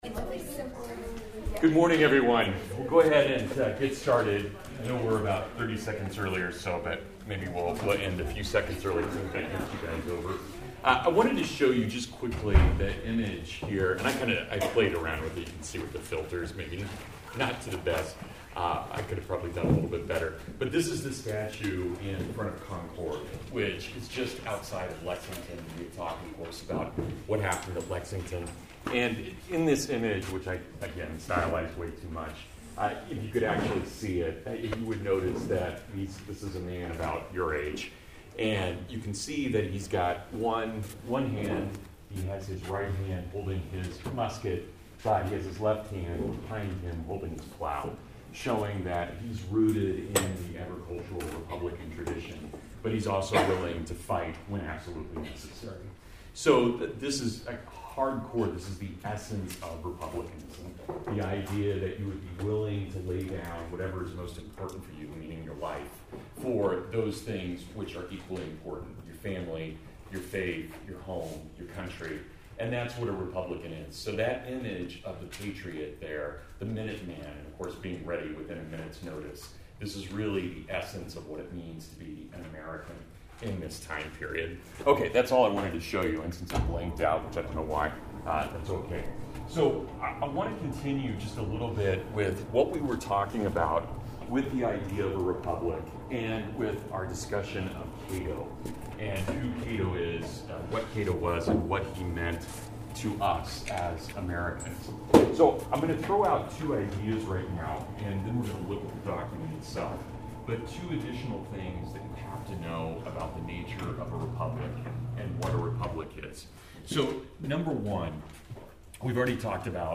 My American Heritage lecture for February 8, 2017: the life of a republic and why it reflects the best in human and divine nature.